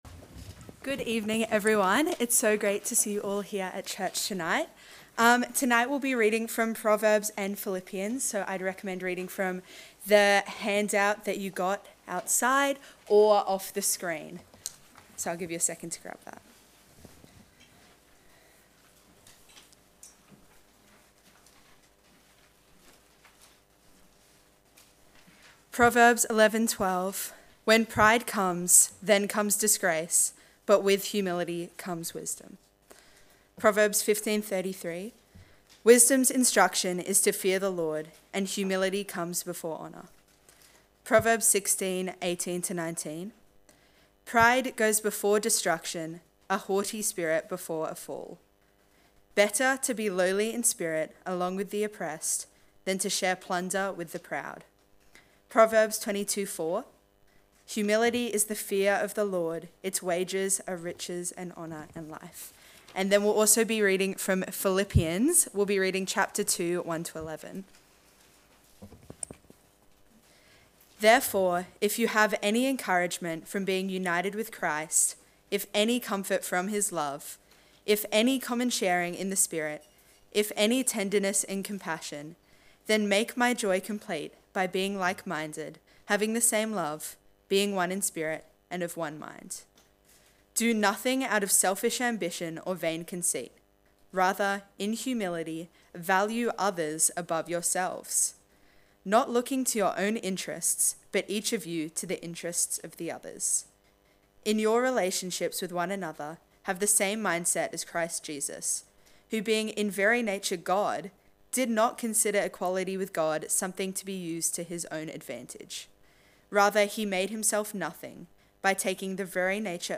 Sermon: Humility